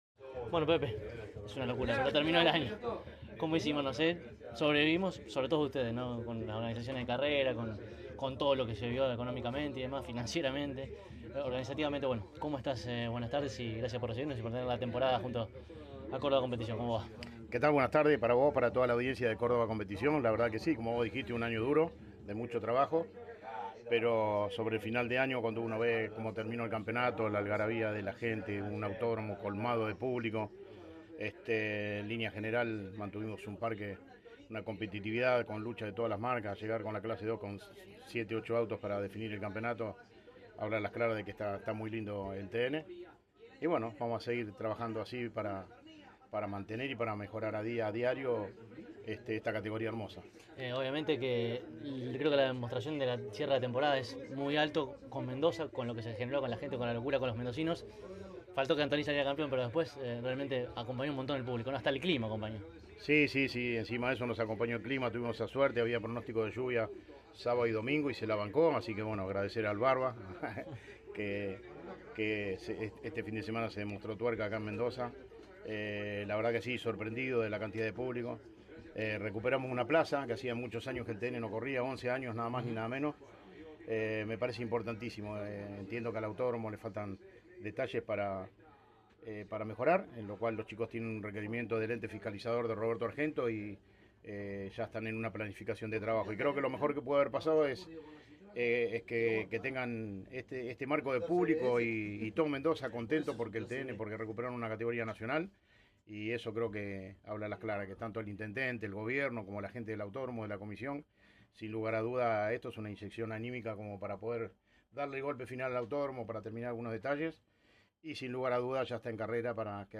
Escuchá, a continuación, el testimonio de ambos, en conjunto: